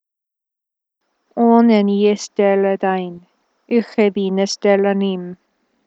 /ˈɔˑ.nɛn   i   ˈɛs.tɛl̡   ˈɛ.dajn/   /ˈuˑ.xɛ.bin   ˈɛs.tɛl̡   ˈa.nim/